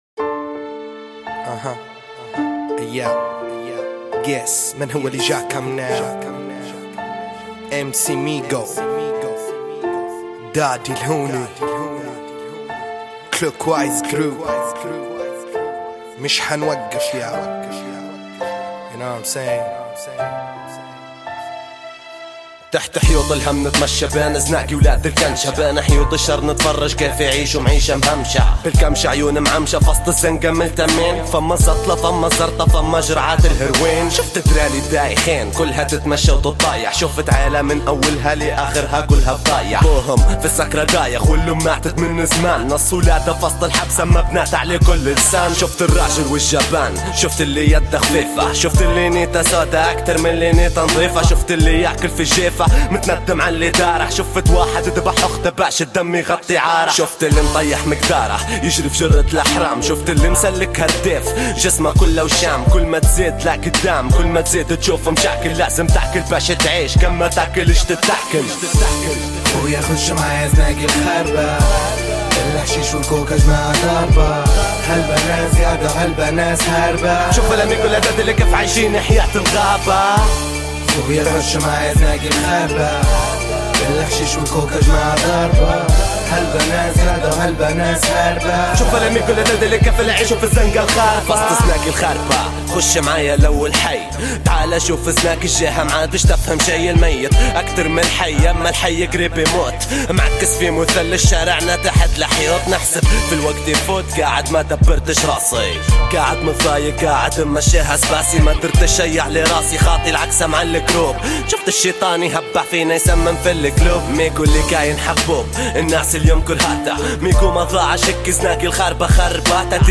libyan rap